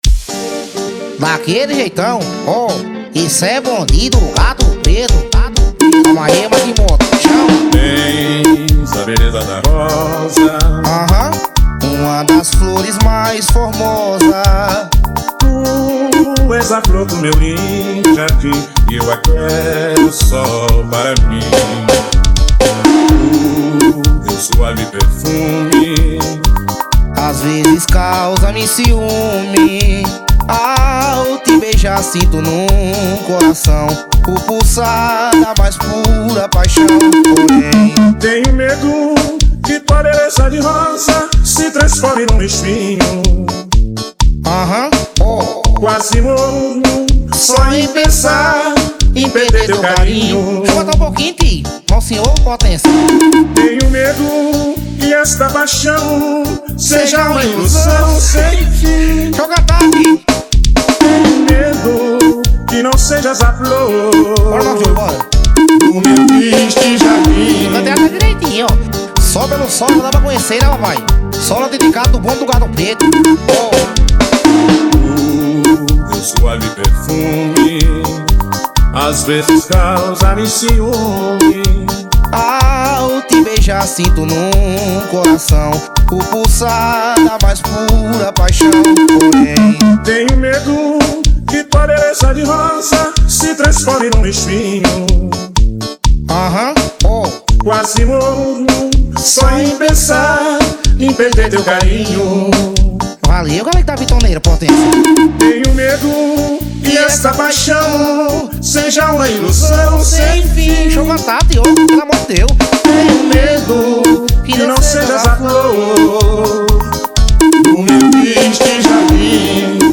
2024-02-16 23:17:55 Gênero: Forró Views